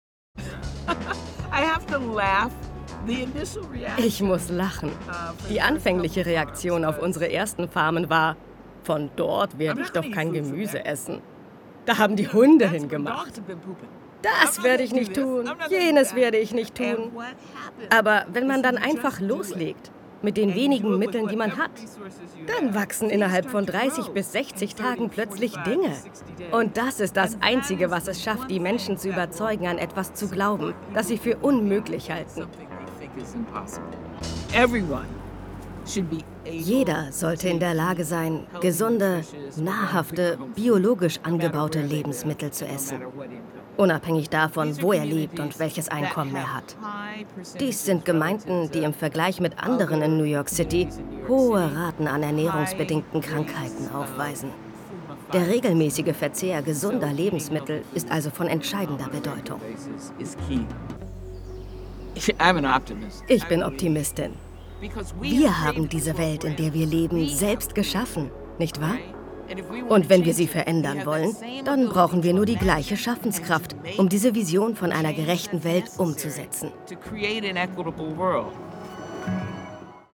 Dokumentation